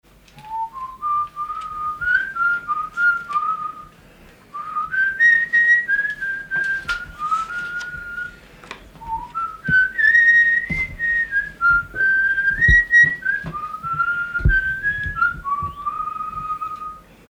Chant sifflé
Pièce musicale inédite